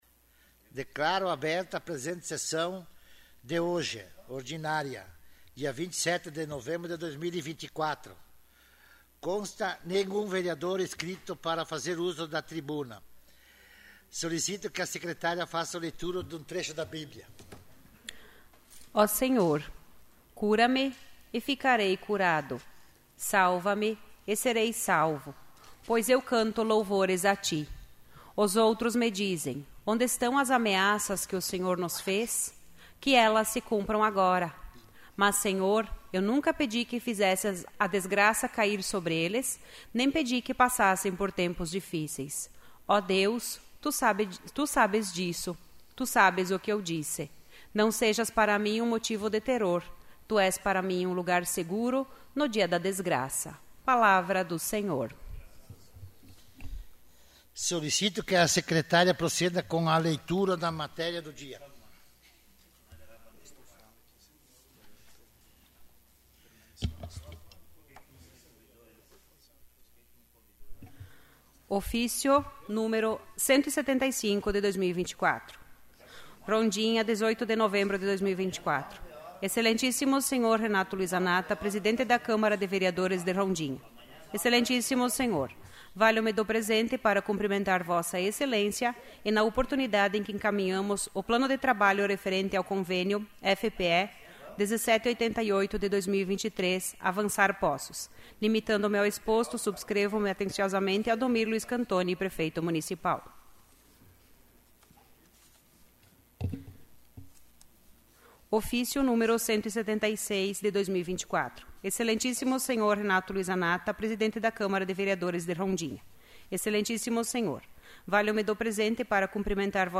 'áudio da sessão do dia 11/02/2026'
No dia 11 de fevereiro de 2026, a Câmara Municipal de Vereadores de Rondinha realizou a segunda sessão extraordinária do ano.
A sessão extraordinária foi presidida pela presidente da Câmara, Veridiane Gesiele Finato, e contou com a presença dos vereadores: Amarildo Antonio Donida, Dirceu Domingos Romani, Eduardo Zorzi, Gilberto Luiz Guilarde, Idemar Vicente Paludo, Renato Luiz Zanatta, todos da bancada do progressistas.